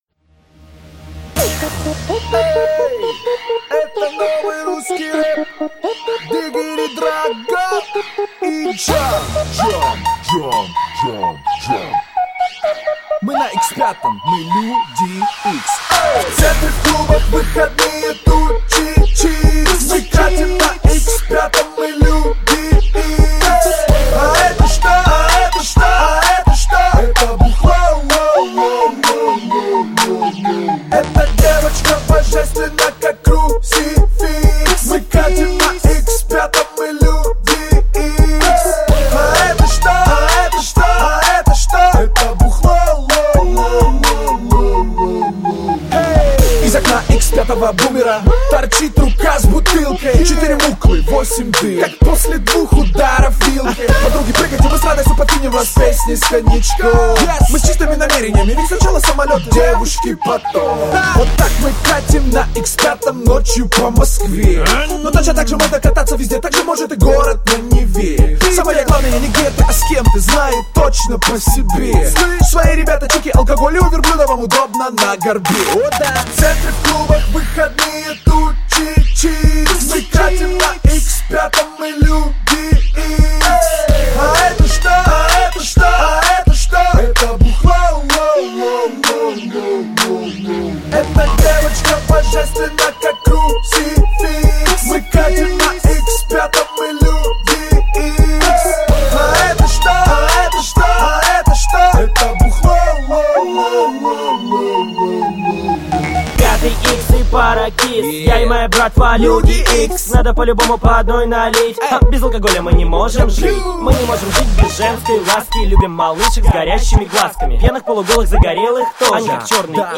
Главная » Русский реп, хип-хоп